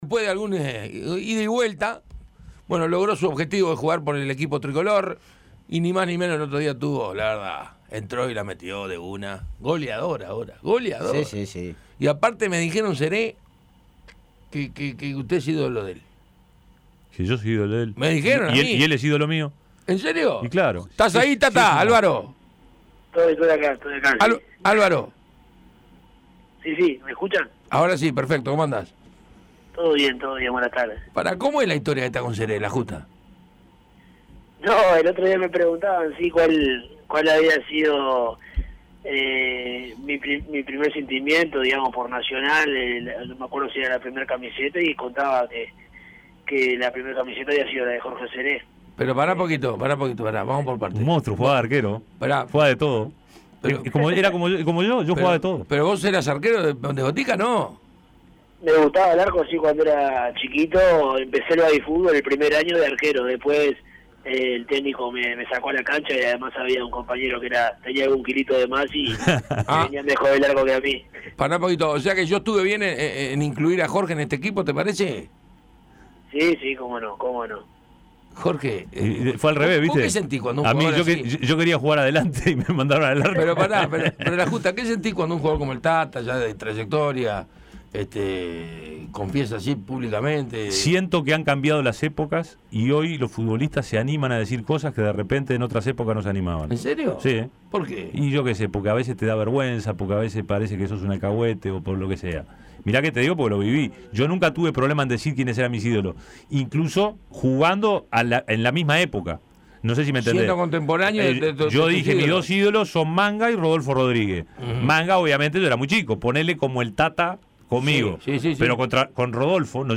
El volante de Nacional y la selección uruguaya, Álvaro "Tata" González, habló de todo en Tuya y Mía. Explicó cómo fue su salida de Lazio para volver al "tricolor", la ilusión que genera la Copa Libertadores, el presente de Nacional, de la selección y recordó el gol que le hizo a Chile cuyo festejo generó mucha repercusión. Aquí la entrevista completa.